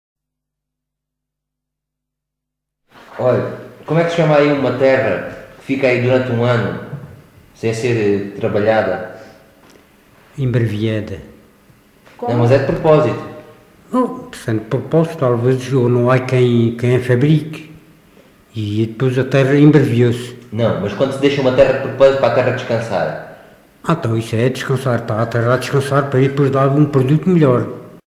LocalidadePorto da Espada (Marvão, Portalegre)